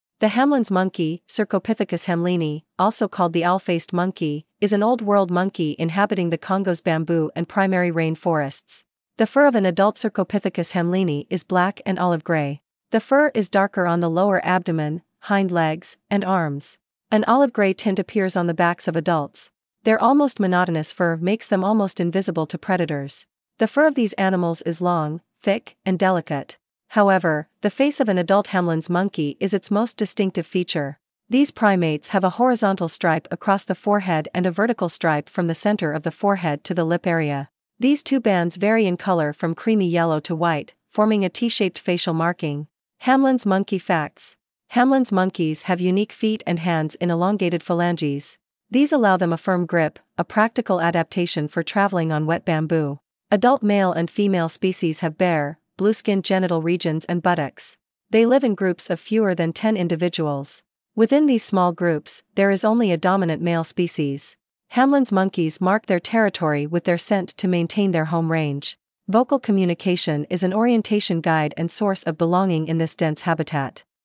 Hamlyn’s Monkey
Hamlyns-Monkey.mp3